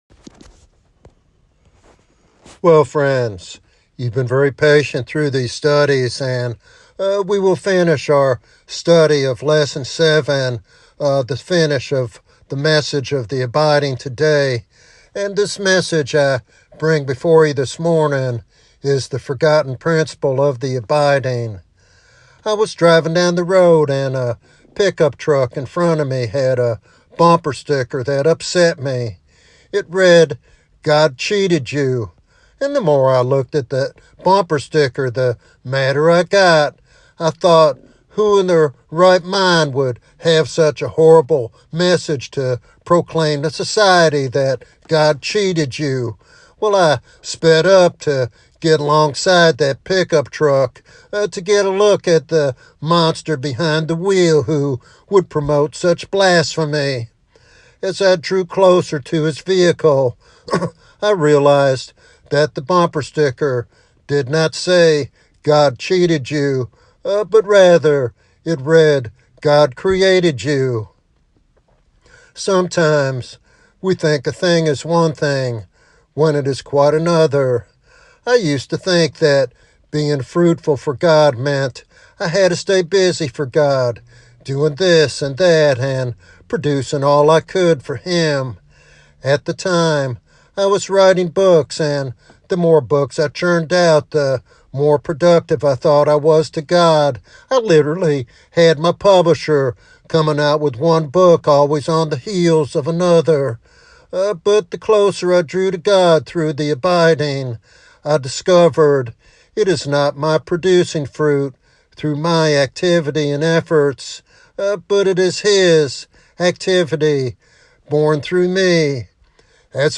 This sermon encourages a deeper fellowship with Jesus and reliance on His power for effective ministry.